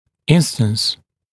[‘ɪnstəns][‘инстэнс]случай, пример